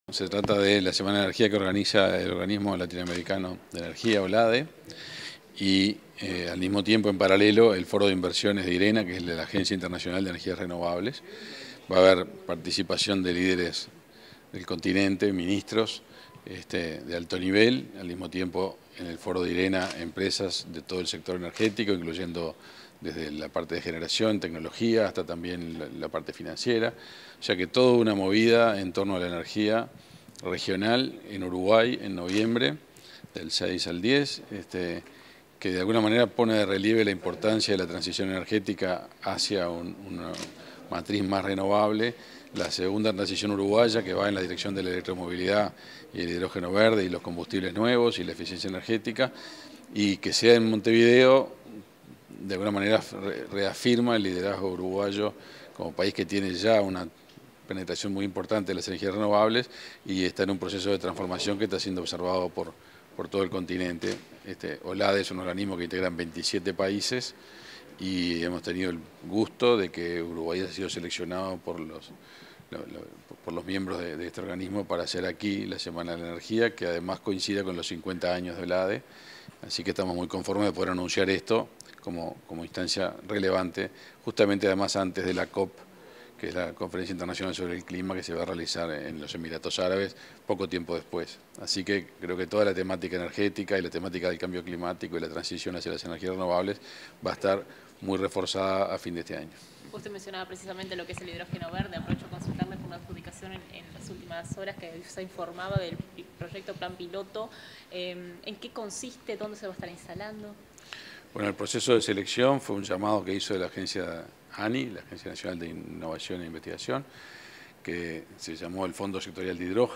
Declaraciones del ministro de Industria, Energía y Minería, Omar Paganini
Declaraciones del ministro de Industria, Energía y Minería, Omar Paganini 17/05/2023 Compartir Facebook X Copiar enlace WhatsApp LinkedIn Tras participar del lanzamiento de la VIII Semana de la Energía, este 17 de mayo, el ministro de Industria, Energía y Minería, Omar Paganini, realizó declaraciones a la prensa.